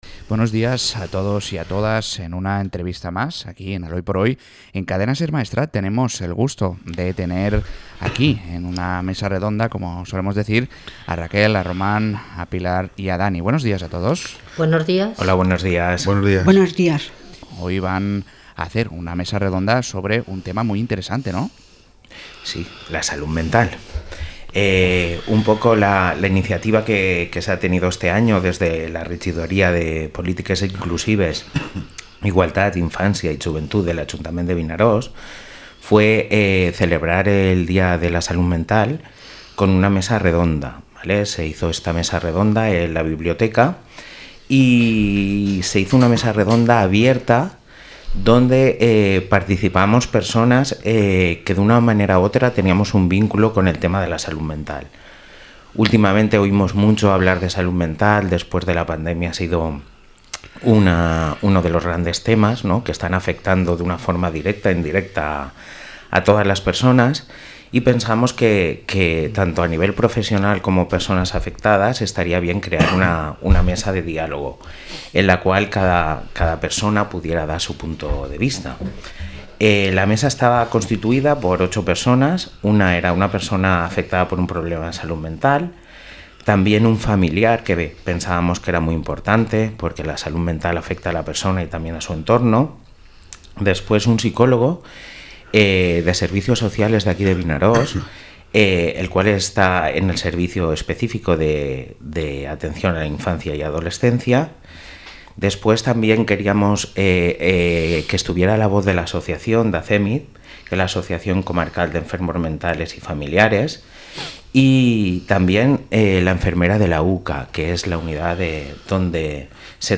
Entrevista a integrants dels CRIS de Vinaròs